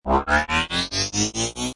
Download Transformer sound effect for free.
Transformer